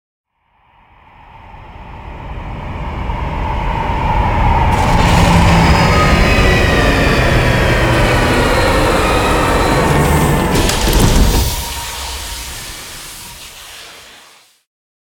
CosmicRageSounds / ogg / ships / movement / landing3.ogg
landing3.ogg